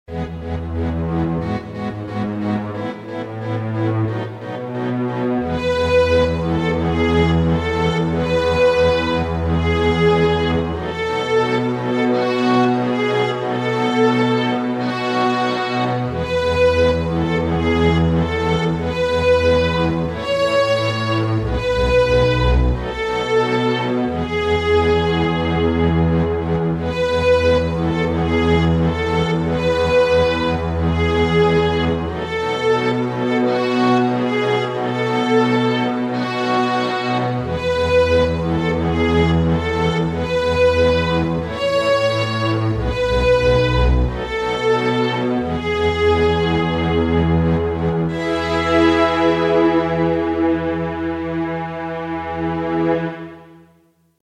This one is a traditional Russian Orthodox Kyrie.
Kyrie-Eleison-Russian.mp3